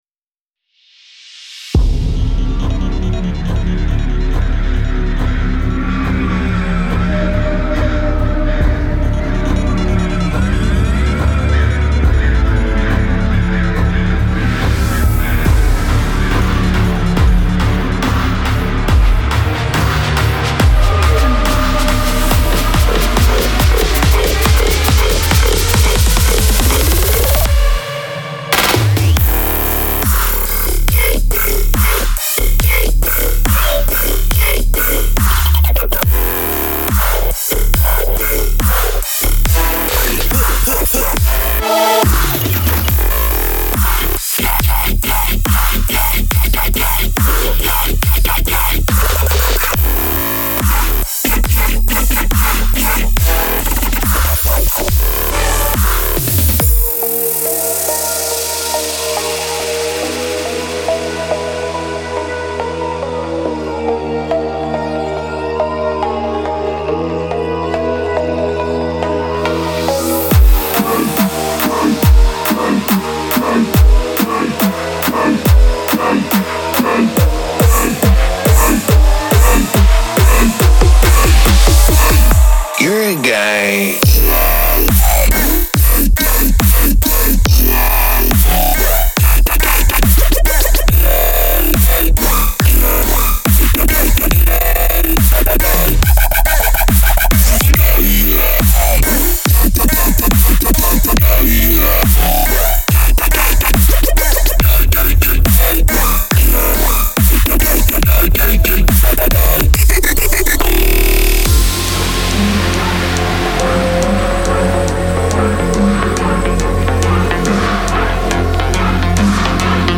RiddimDubstep
些最肮脏的引线，这些都打入了样本市场。